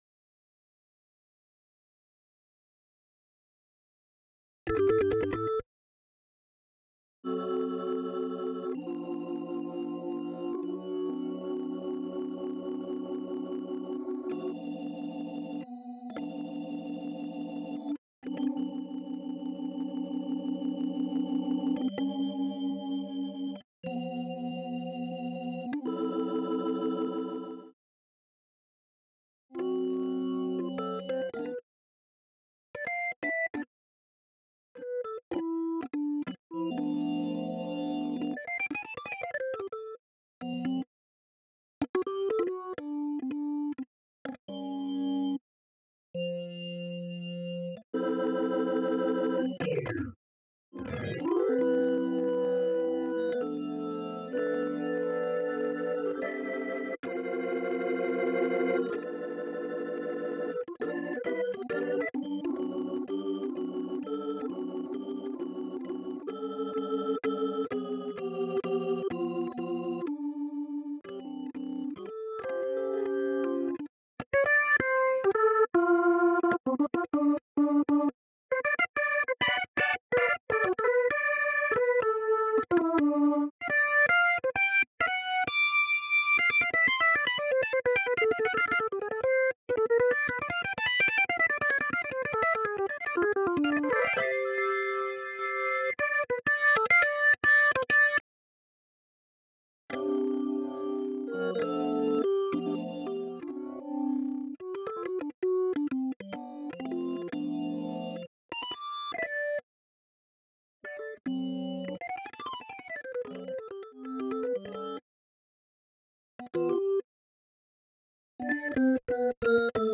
16_Organ.wav